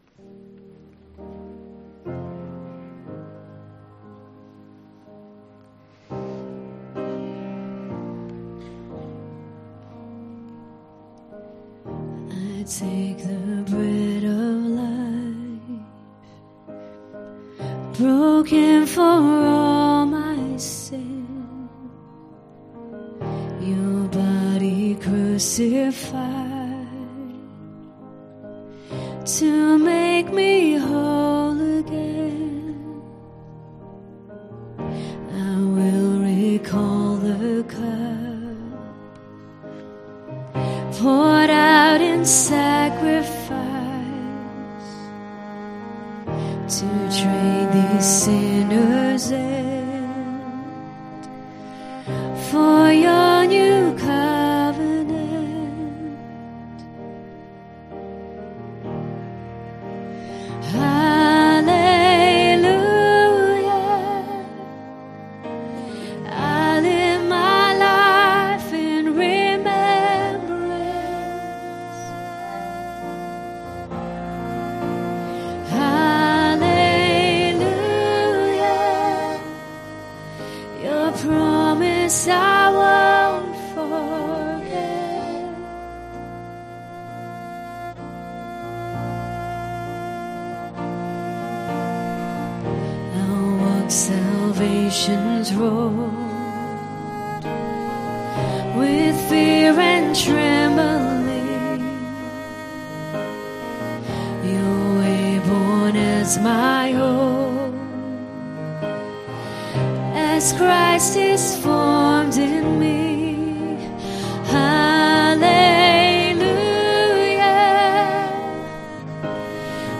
Ministry Song https